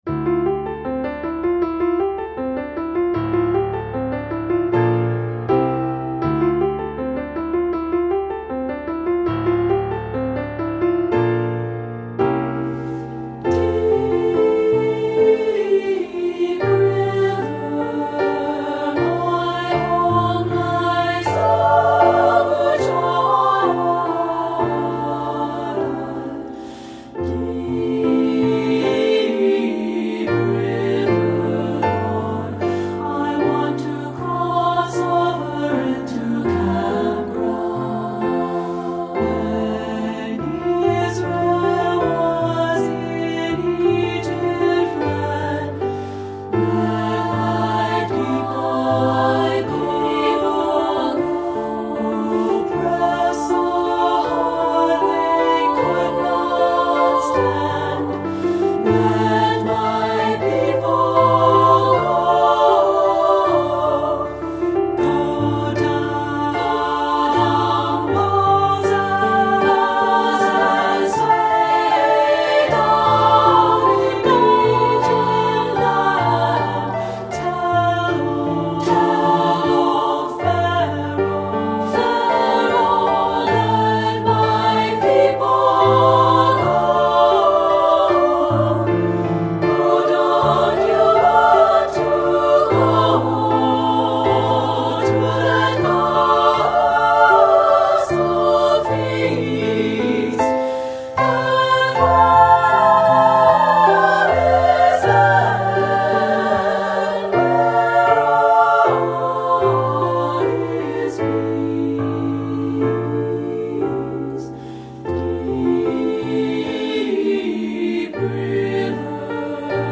Composer: African American Spiritual
Voicing: SSA and Piano